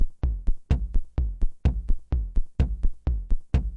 描述：127 bpm drum loop